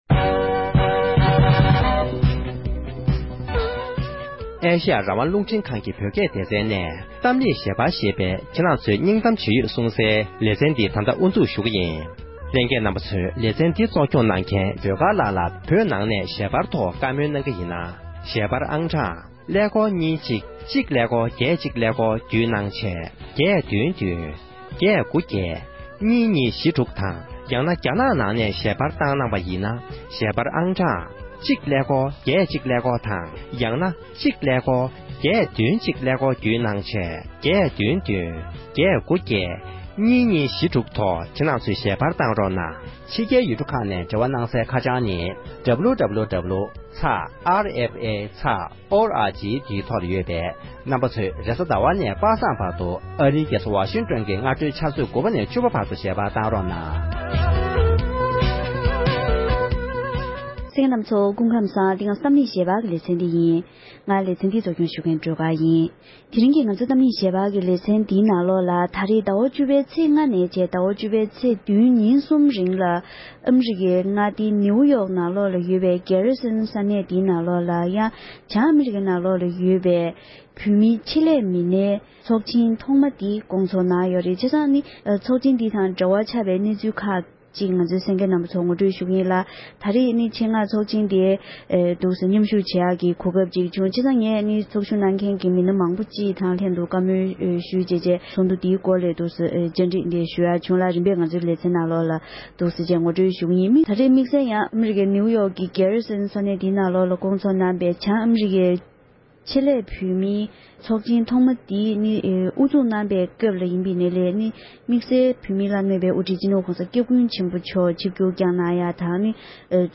༄༅༎དེ་རིང་གི་གཏམ་གླེང་ཞལ་པར་གྱི་ལེ་ཚན་ནང་བོད་མིའི་བླ་ན་མེད་པའི་དབུ་ཁྲིད་སྤྱི་ནོར་༸གོང་ས་༸སྐྱབས་མགོན་ཆེན་པོ་མཆོག་ནས་ཨ་རིའི་མངའ་སྡེ་ནིའུ་ཡོརྐ་ནང་སྐོང་ཚོགས་གནང་བའི་བྱང་ཨ་རིའི་བོད་མི་ཆེད་ལས་པའི་ལྷན་ཚོགས་ཐོག་བཀའ་སློབ་བསྩལ་ཡོད་པའི་སྐོར་བགྲོ་གླེང་ཞུས་པ་ཞིག་གསན་རོགས༎